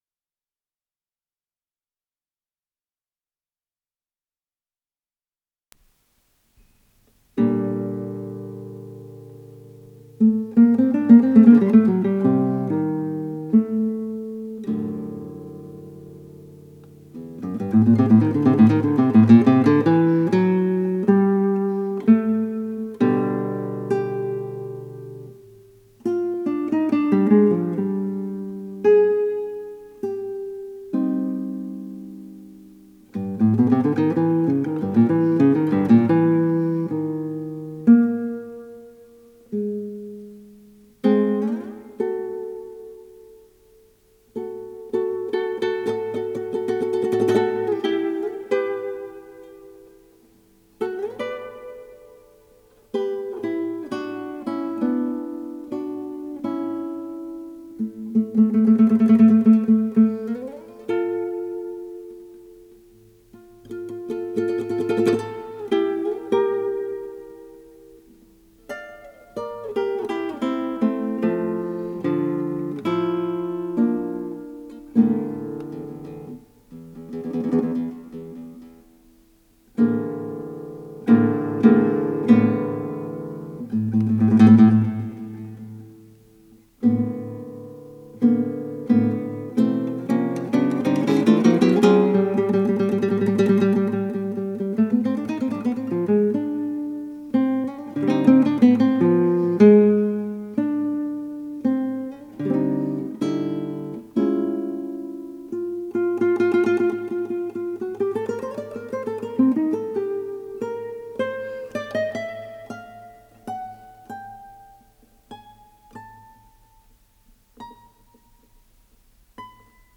с профессиональной магнитной ленты
шестиструнная гитара